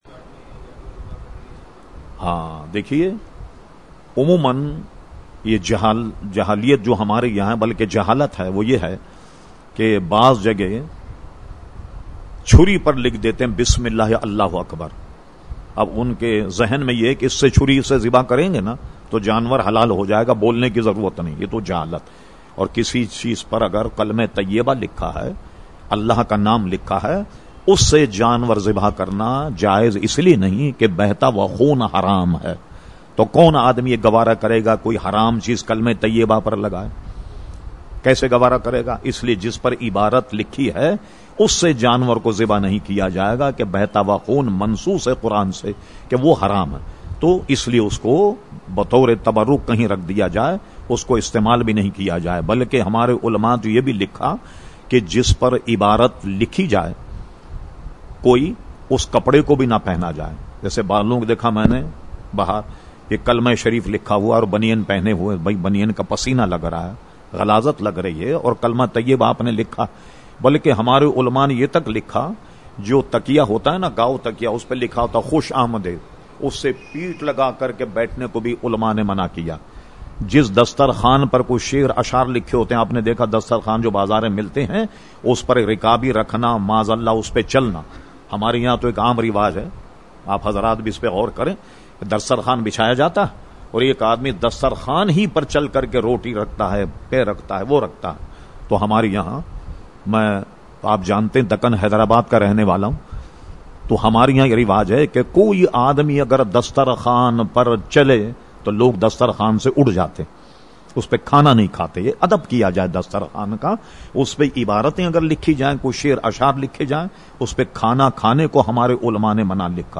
Q/A Program held on Sunday 6 November 2011 at Masjid Habib Karachi.